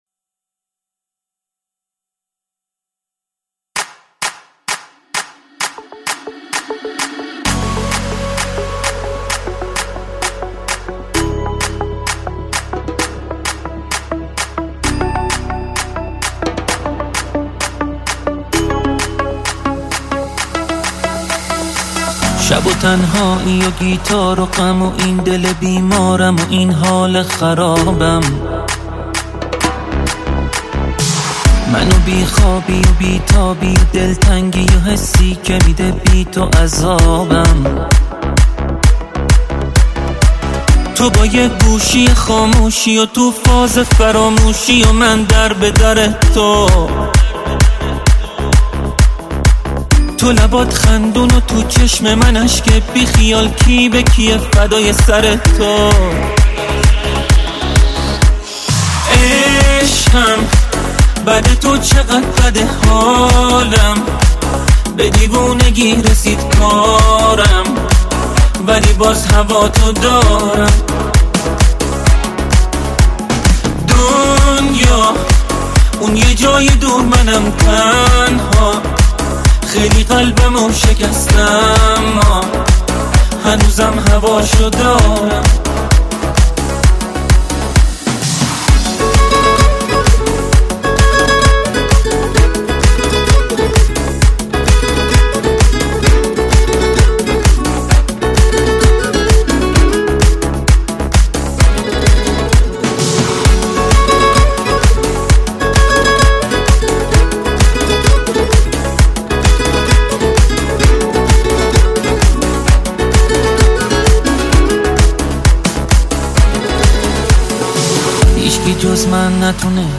آهنگ شاد رقصی